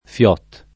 FIOT [ f L0 t ]